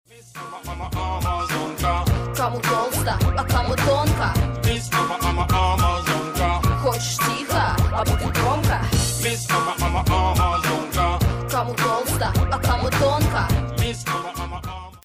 Главная » рингтоны на телефон » Рэп, Хип-Хоп, R'n'B